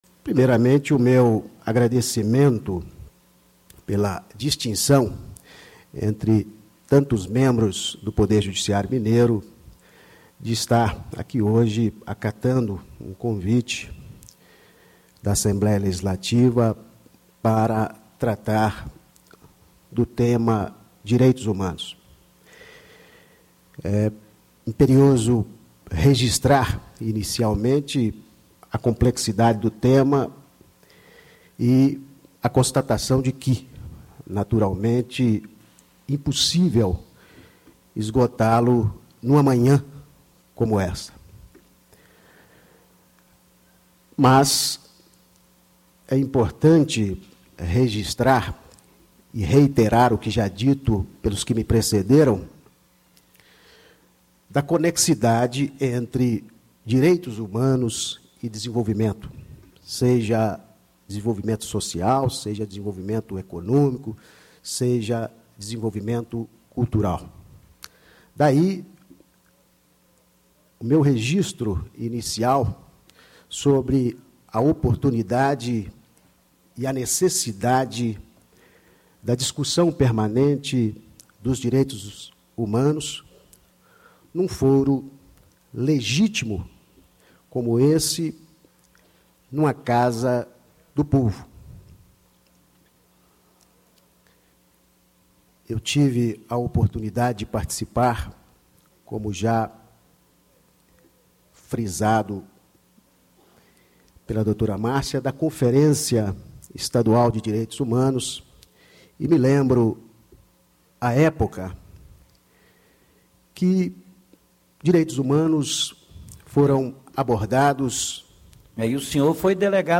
Herbert Carneiro, Desembargador do Tribunal de Justiça de Minas Gerais
Discursos e Palestras